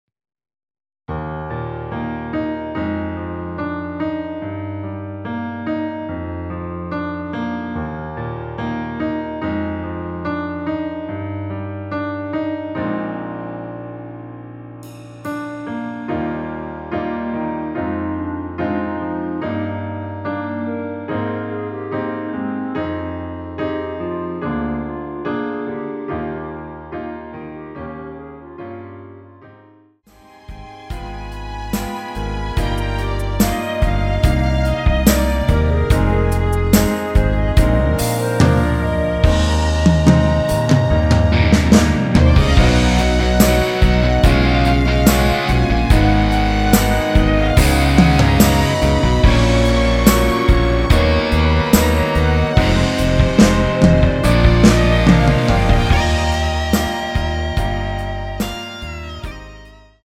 원키 멜로디 포함된 MR입니다.(미리듣기 확인)
Eb
앞부분30초, 뒷부분30초씩 편집해서 올려 드리고 있습니다.
중간에 음이 끈어지고 다시 나오는 이유는